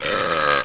BURP.mp3